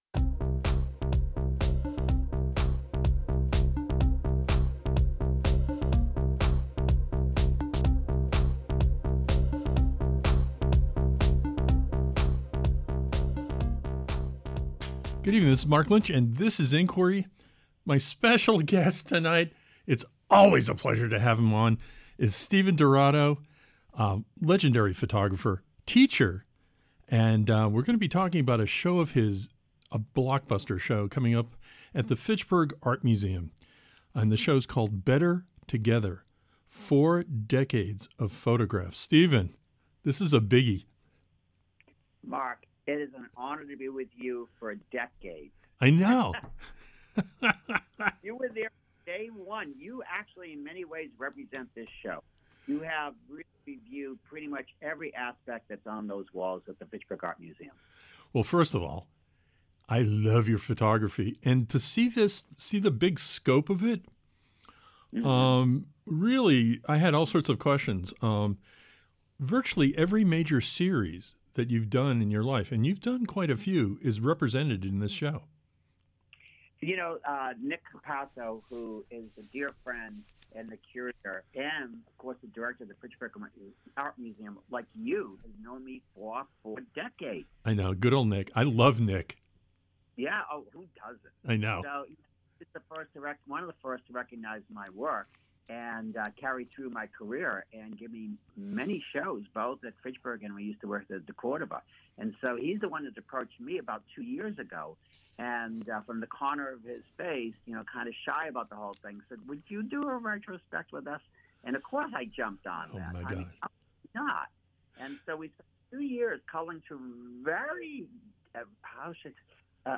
Conversation Extra.